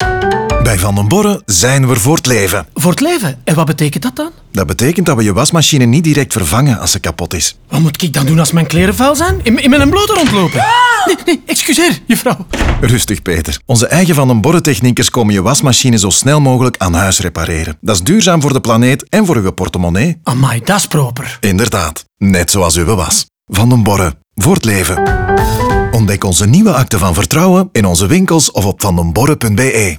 Zo gaat de voice-over telkens in dialoog met verschillende klanten en helpt hij hen met al hun vragen.
Last but not least kreeg ook het soundlogo een lichte make-over.